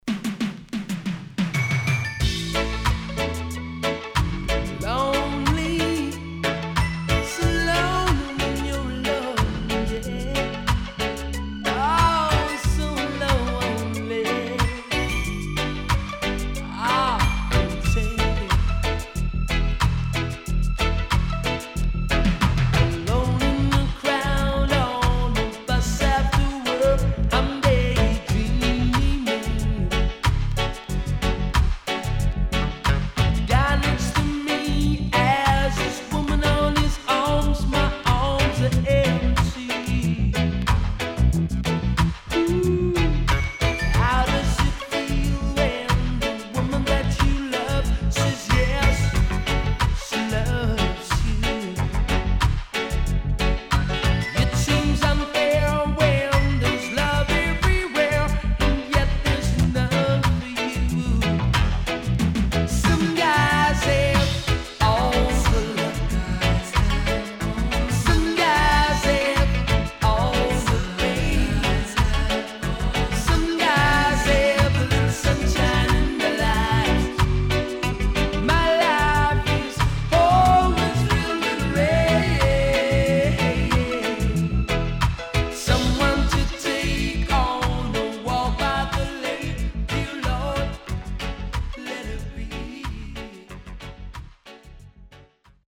SIDE B:所々チリノイズがあり、少しプチノイズ入ります。